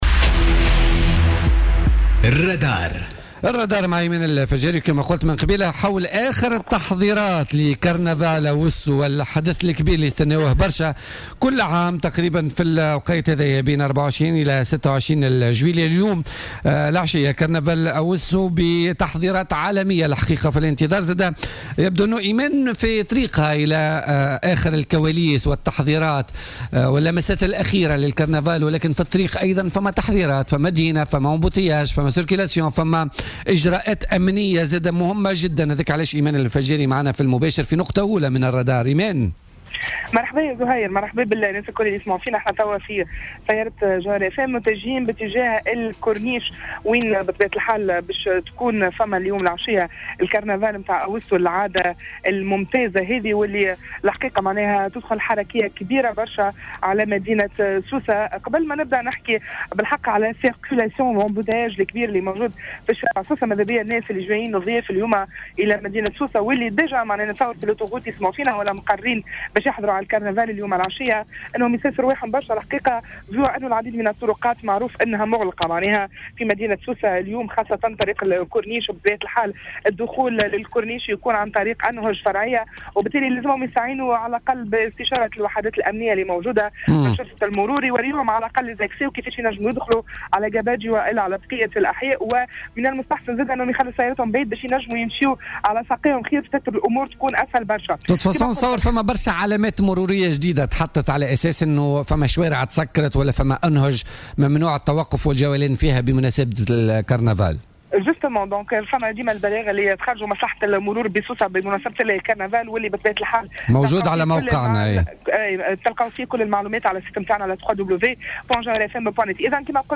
تحول فريق فقرة "الرادار" اليوم إلى شاطئ بوجعفر لمعاينة آخر الاستعدادات قبل انطلاق "كرنفال" مهرجان "أوسو".